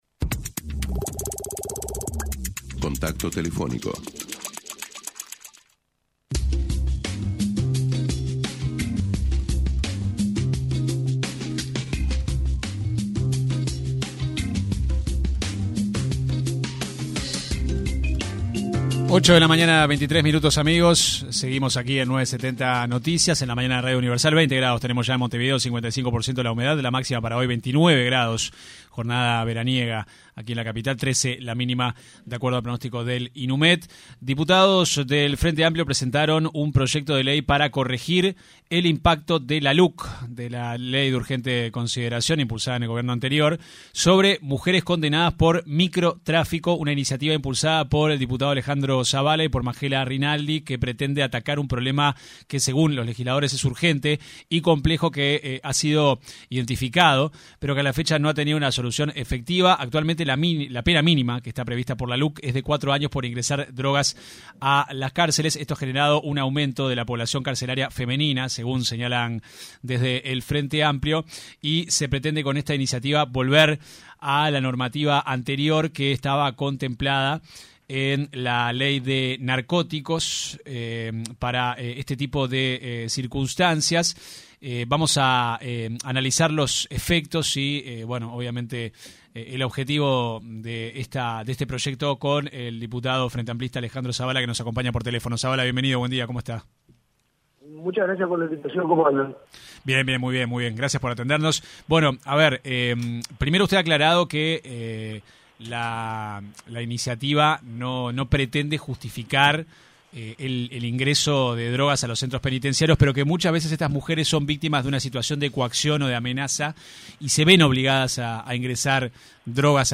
El diputado por el Frente Amplio, Alejandro Zavala, se refirió en una entrevista con 970 Noticias, al proyecto de ley que impulsan desde la bancada oficialista que tiene que ver con corregir el impacto de la Luc sobre las mujeres condenadas por microtráfico de drogas.